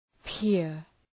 peer Προφορά
{pıər}